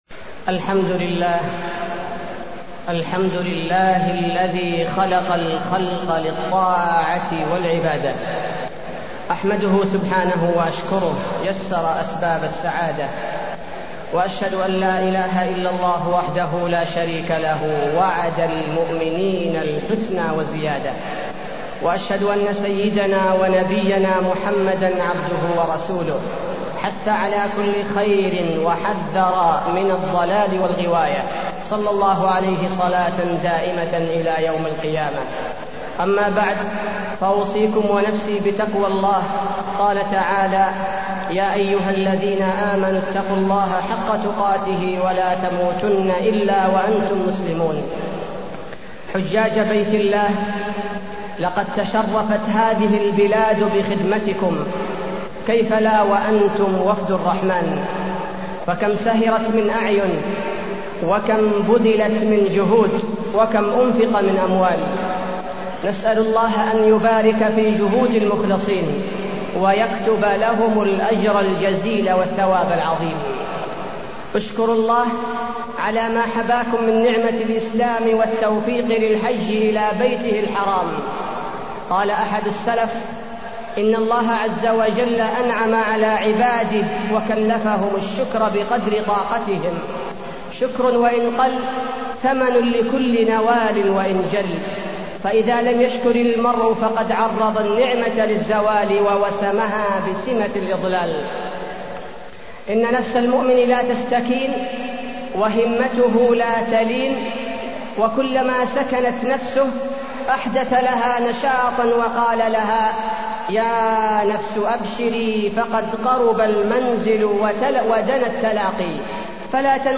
تاريخ النشر ٢١ ذو الحجة ١٤٢١ هـ المكان: المسجد النبوي الشيخ: فضيلة الشيخ عبدالباري الثبيتي فضيلة الشيخ عبدالباري الثبيتي نصائح للحجاج